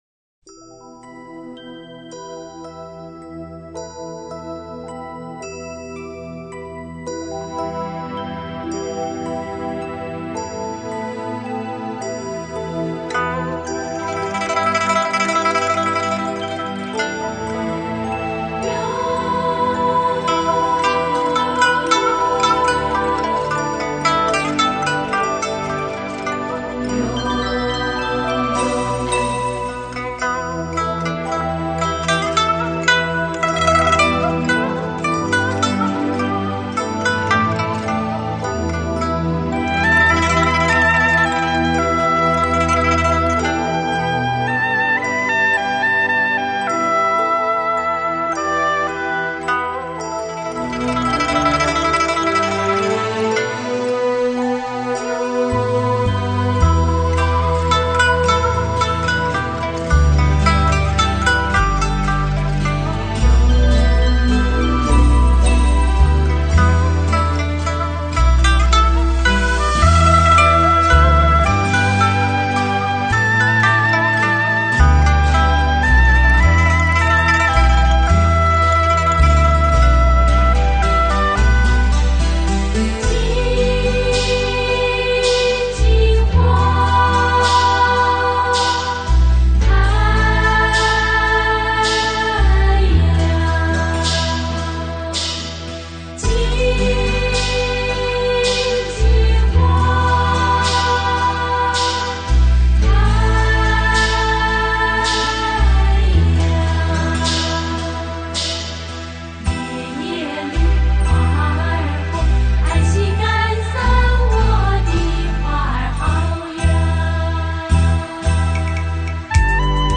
而芒鑼敲起、蘆管鳴響，更顯露出山林的原始與神秘。